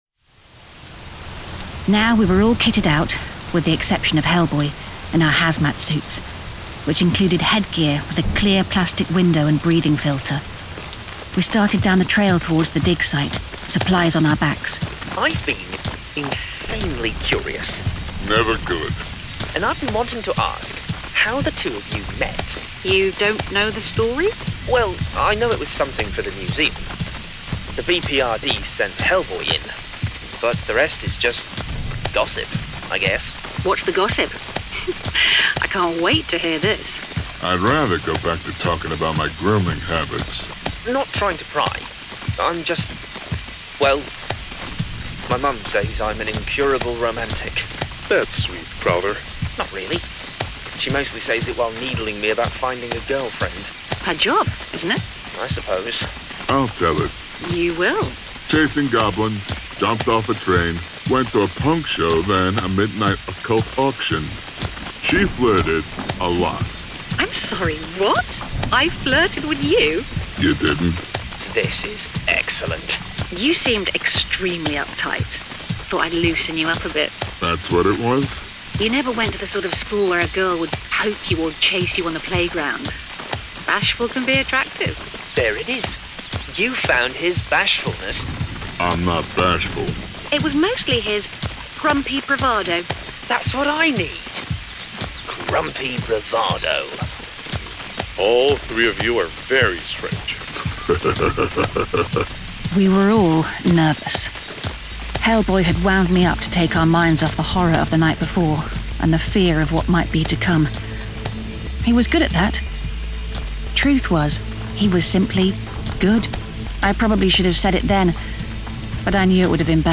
Full Cast. Cinematic Music. Sound Effects.
Genre: Horror
This Original Dramatized Audiobook continues the story from HELLBOY IN LOVE.
Written exclusively for GraphicAudio and produced with a full cast of actors, immersive sound effects and cinematic music!